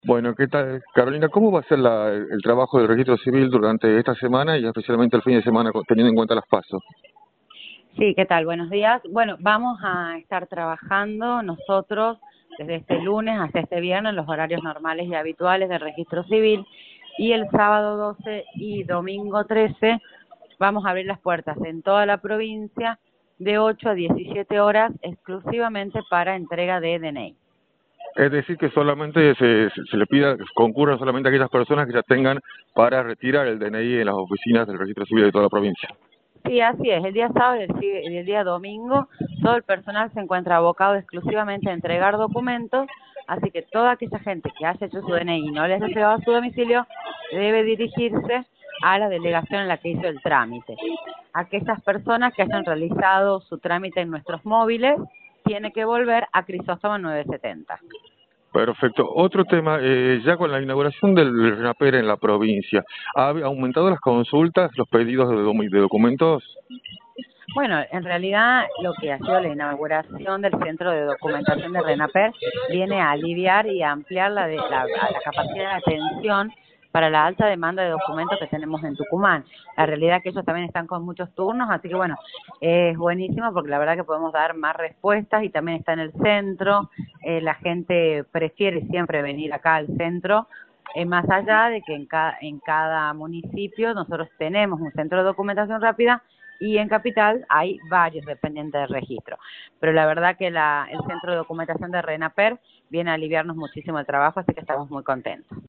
Carolina Bidegorry, titular del Registro Civil de la provincia, indicó en Radio del Plata Tucumán, por la 93.9, cómo será el funcionamiento de la institución durante esta semana previa a las elecciones PASO.